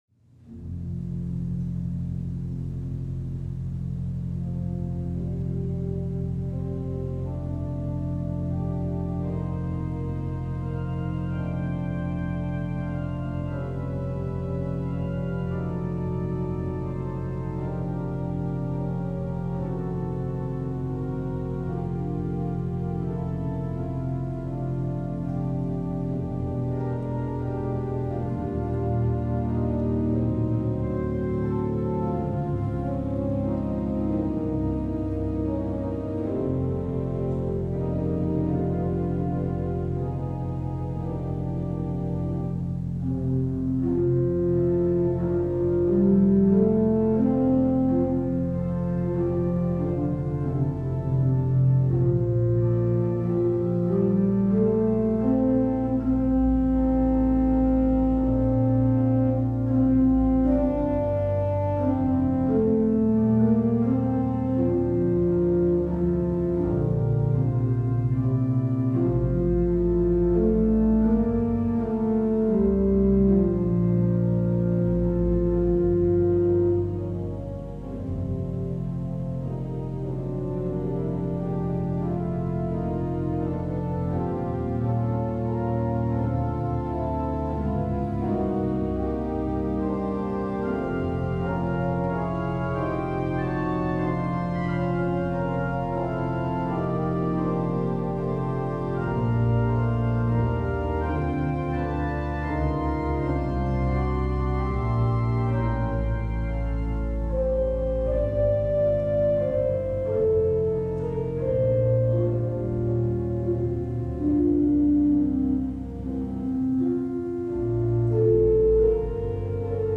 Sanctuary-July-12-audio.mp3